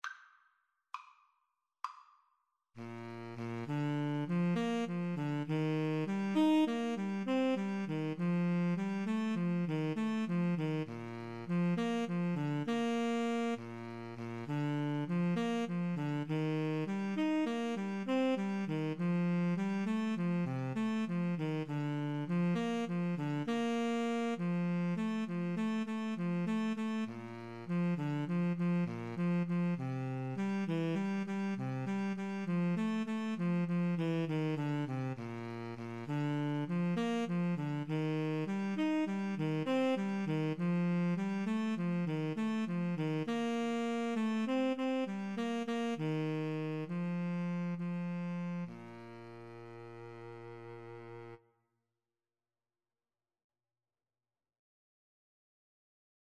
9/8 (View more 9/8 Music)
Moderato